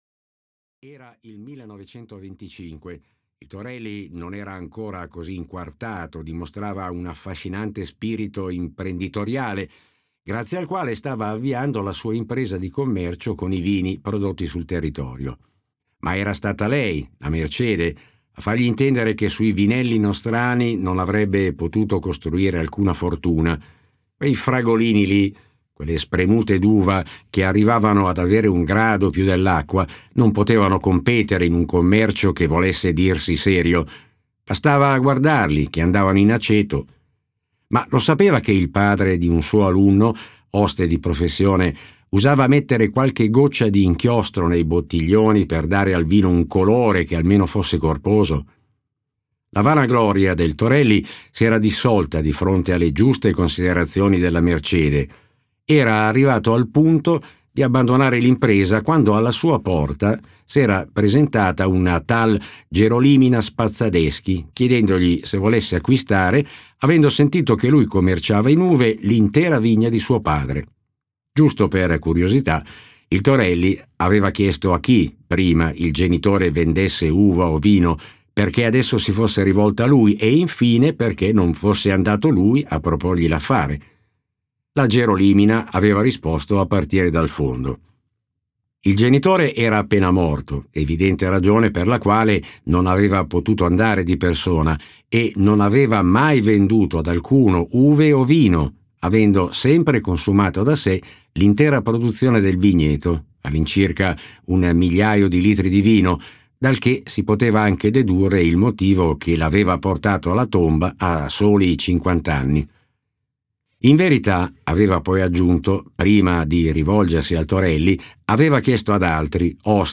"Biglietto, signorina" di Andrea Vitali - Audiolibro digitale - AUDIOLIBRI LIQUIDI - Il Libraio
• Letto da: Andrea Vitali